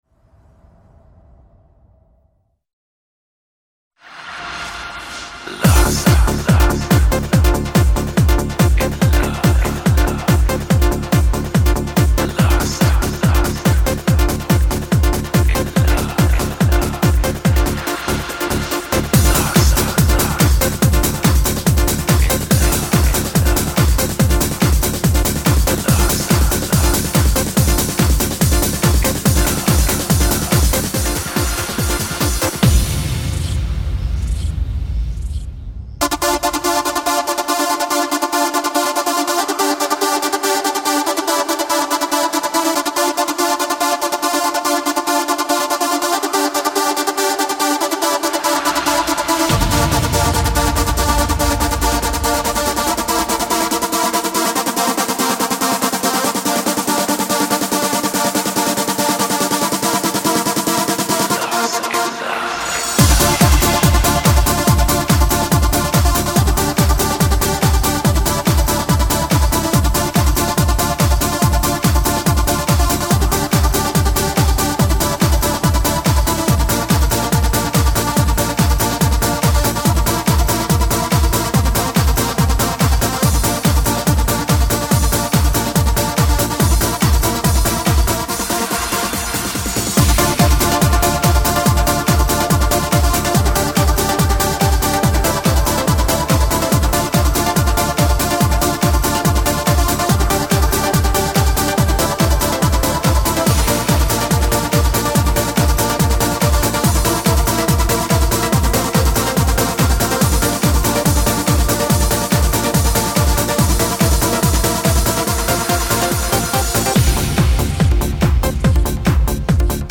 Жанр: Electronica-Trance